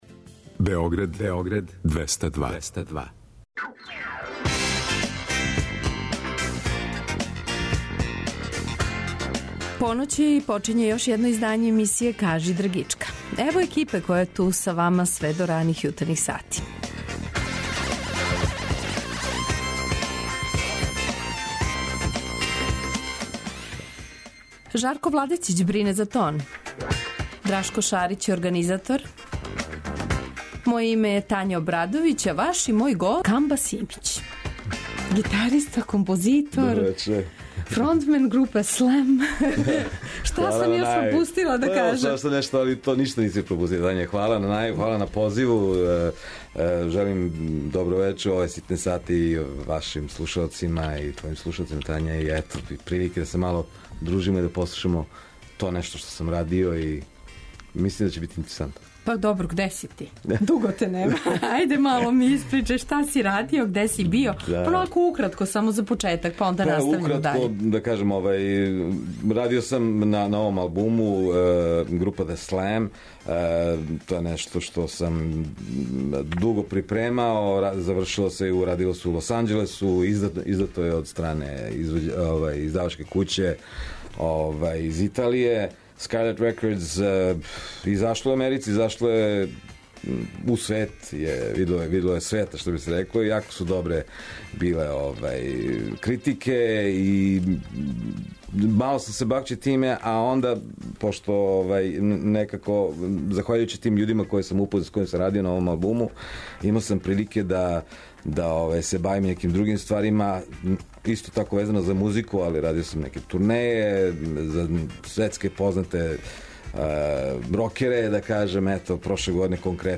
Гост: гитариста